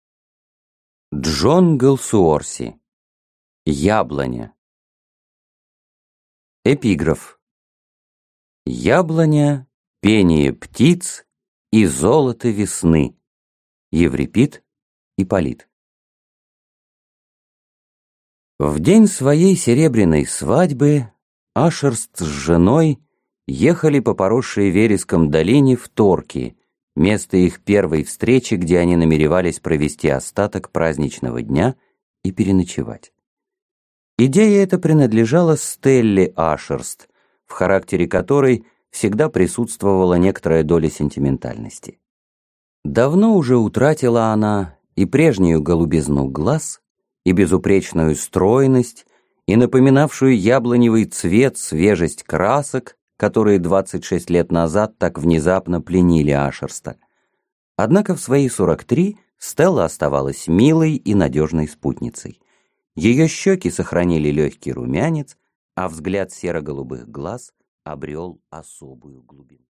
Аудиокнига Яблоня | Библиотека аудиокниг